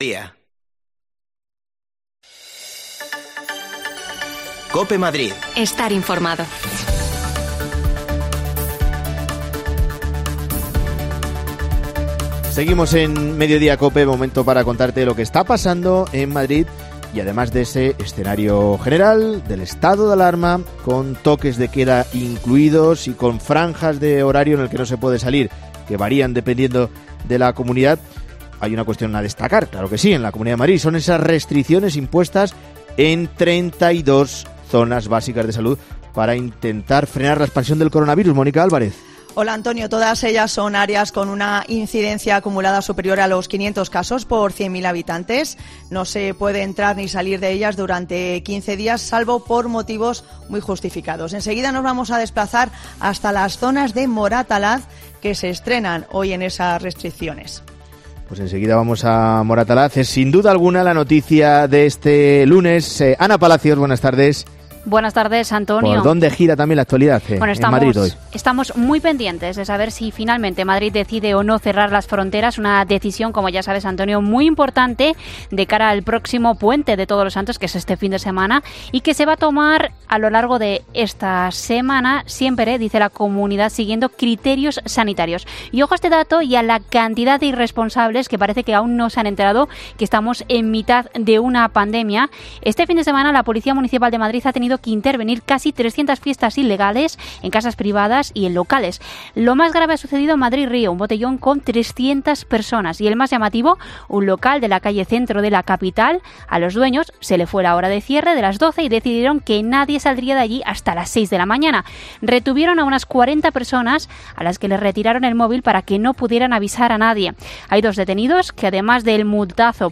AUDIO: Nos vamos a Moratalaz a charlar con los vecinos sobre sus nuevas restricciones que sufren desde hoy